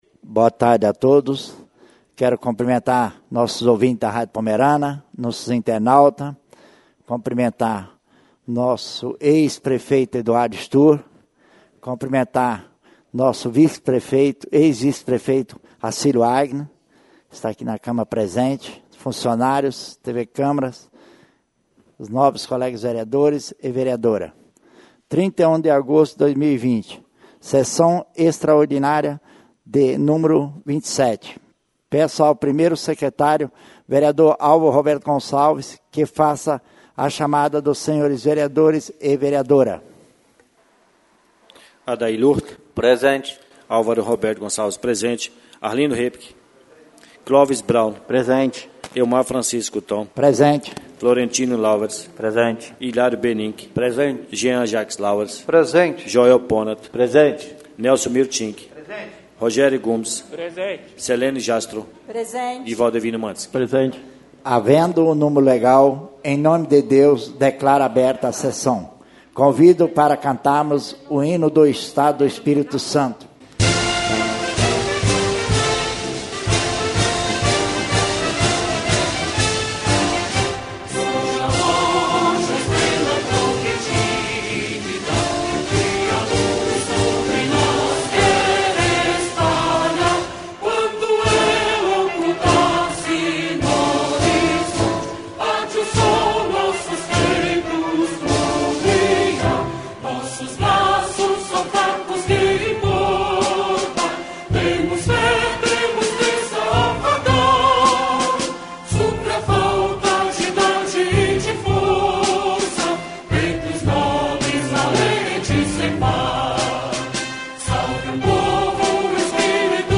SESSÃO EXTRAORDINÁRIA Nº 27/2020